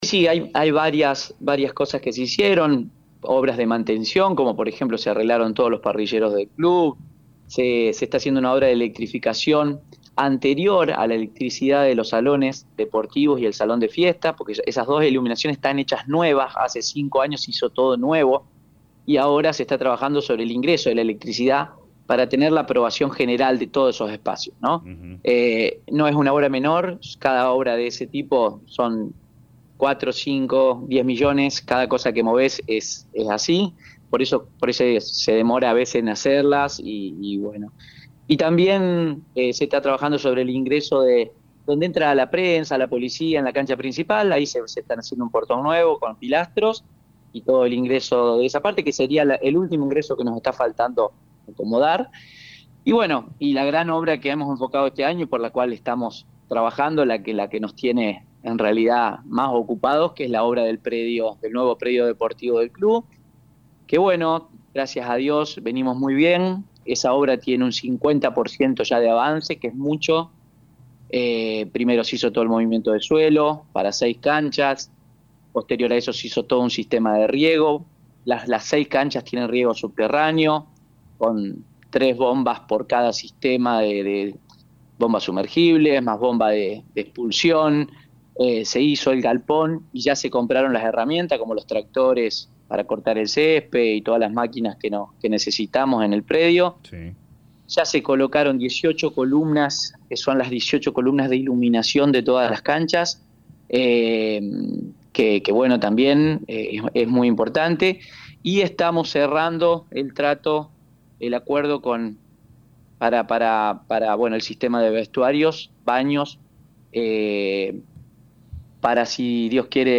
en dialogo con LA RADIO 102.9